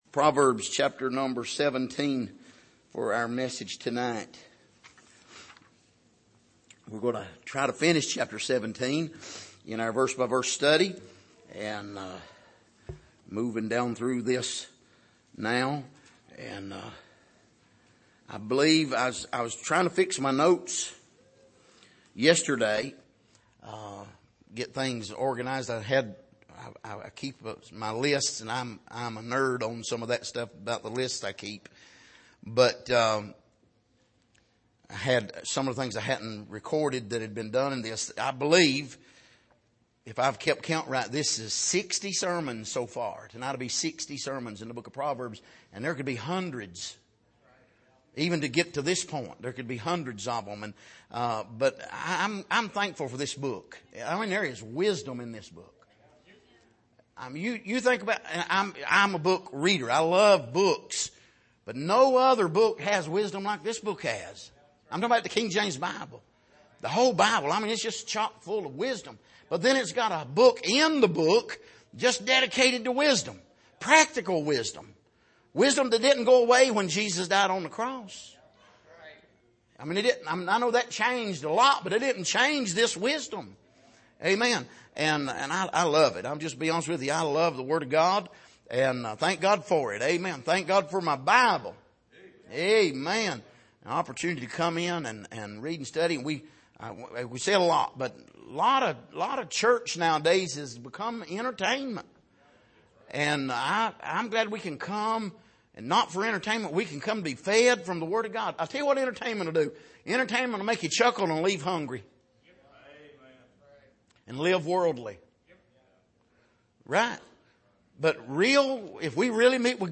Passage: Proverbs 17:22-28 Service: Sunday Evening